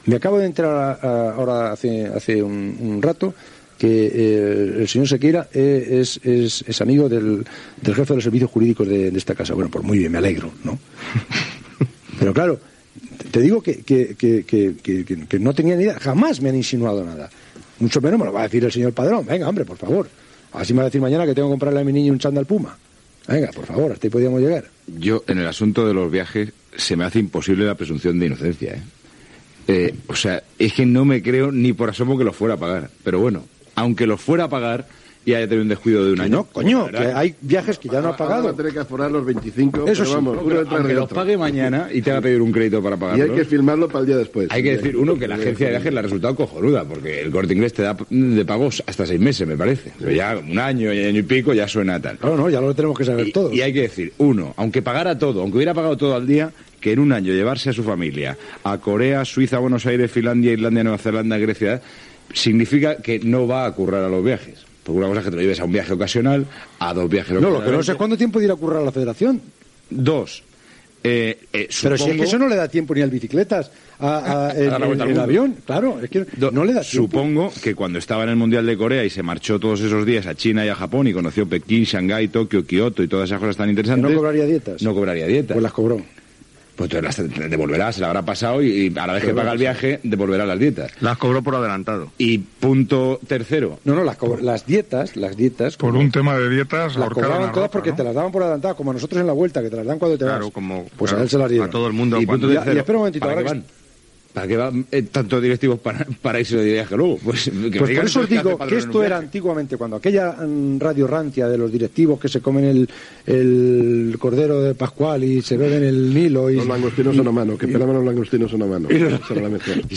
Comentaris sobre Juan Padrón, vicepresident de la Federació de Futbol, protagonista de nombrosos escàndols de corrupció. Valoracions dels contertulis.
Esportiu